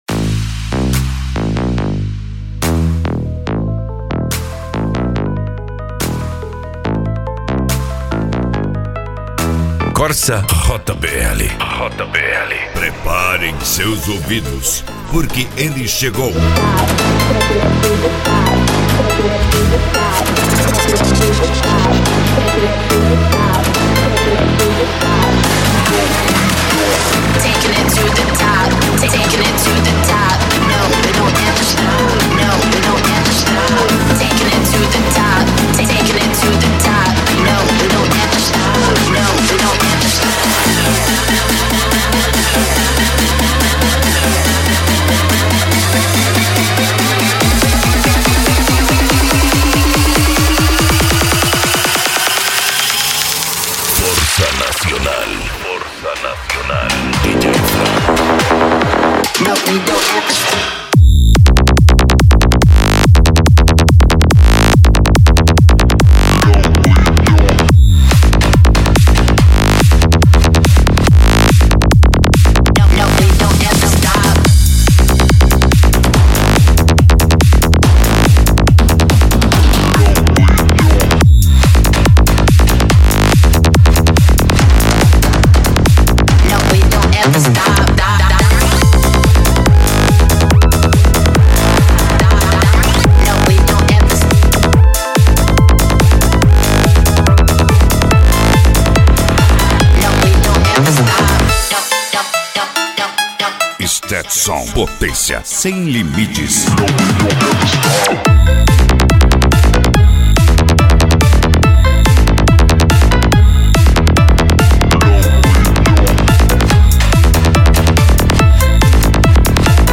Eletronica
PANCADÃO
Psy Trance